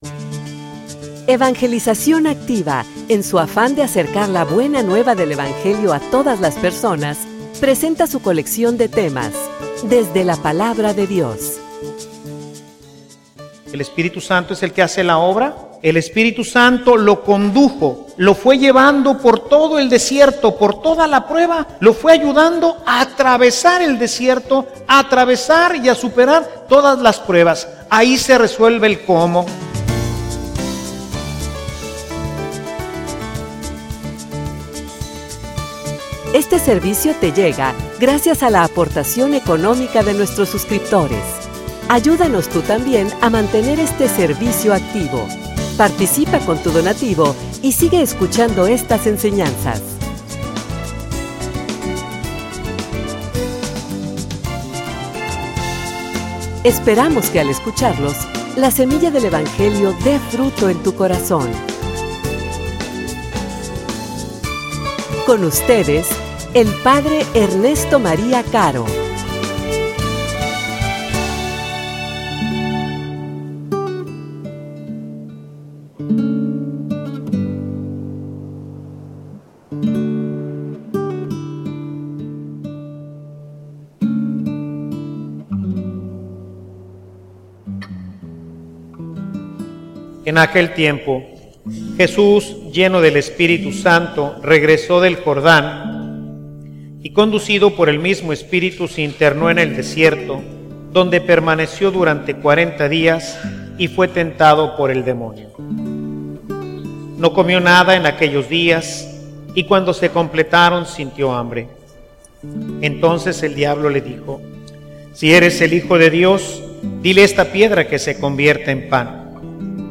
homilia_Conducidos_por_el_Espiritu.mp3